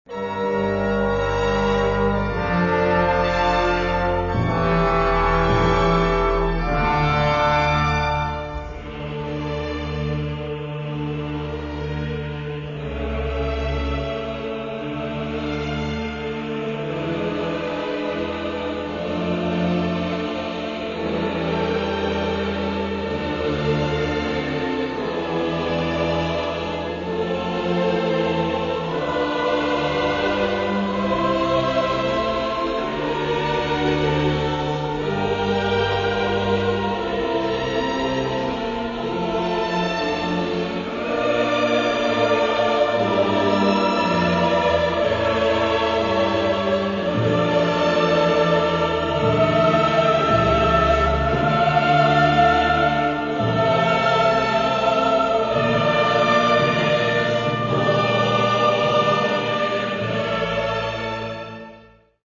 Catalogue -> Classical -> Choral Art